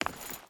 Footsteps / Stone
Stone Chain Run 4.wav